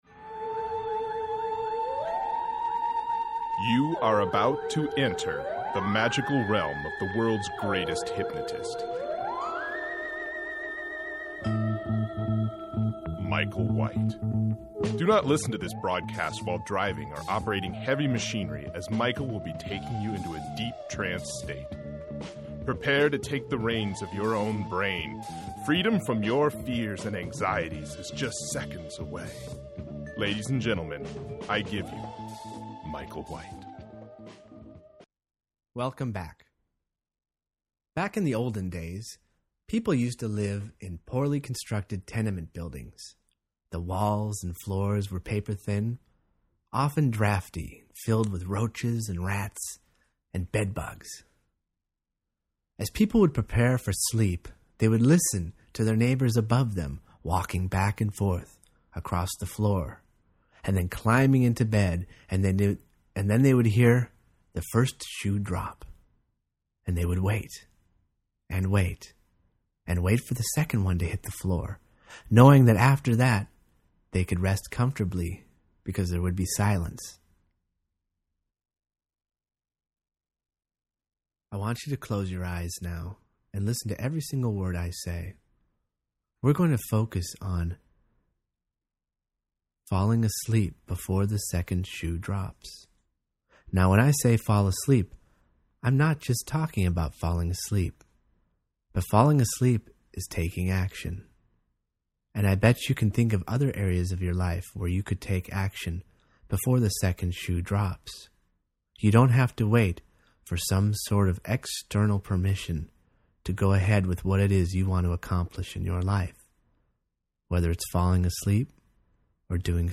Hypnosis